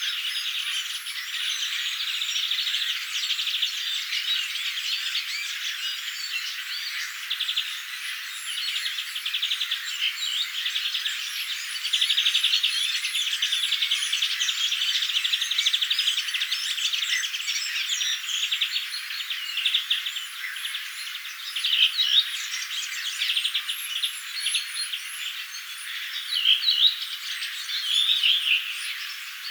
sinitiainen visertelee hyvin korkealta
ehka_sinitiaisen_hyvin_korkeata_visertelya.mp3